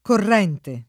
[ korr $ nte ]